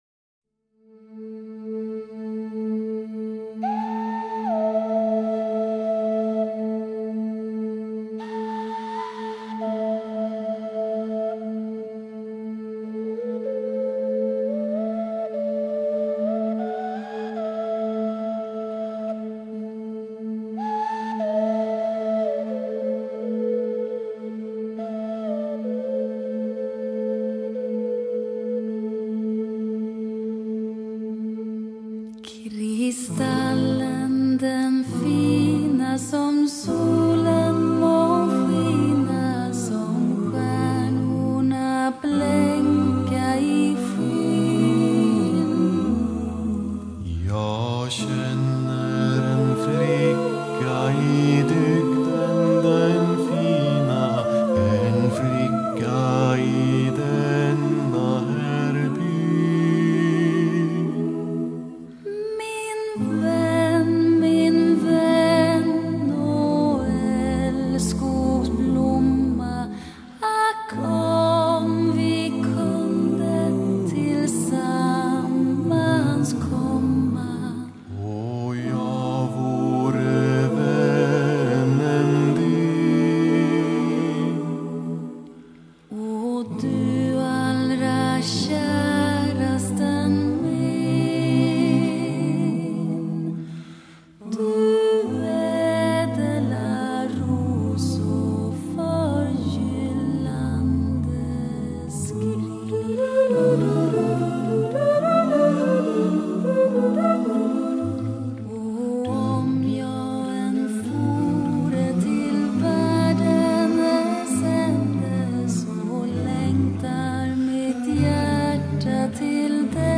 Accompaniment:      A Cappella
Music Category:      Vocal Jazz